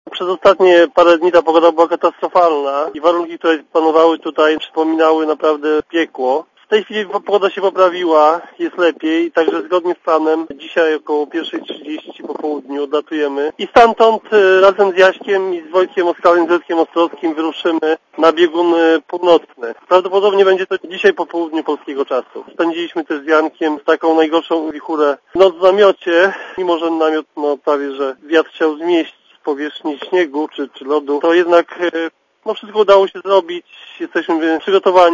Wsiadamy do samolotu i lecimy na dryfującą stację na oceanie arktycznym - powiedział Radiu Zet Marek Kamiński, który jest już z Jankiem Melą na Spitsbergenie.